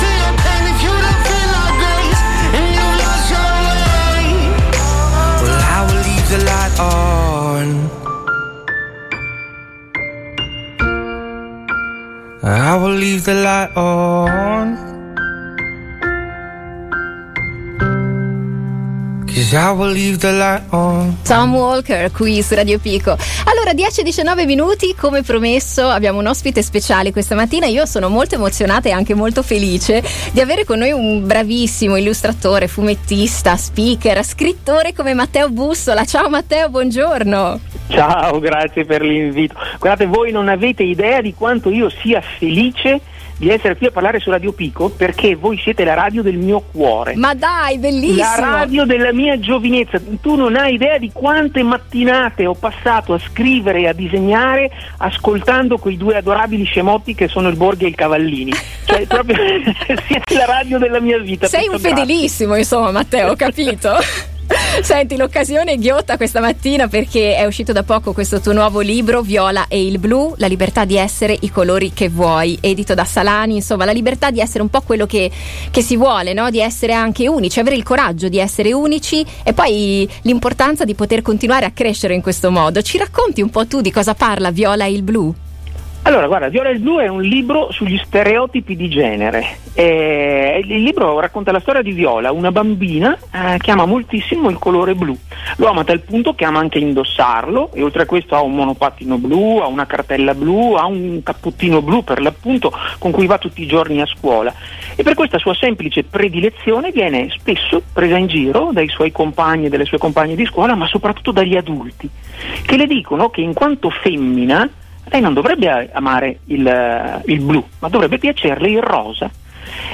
Passepartout intervista Matteo Bussola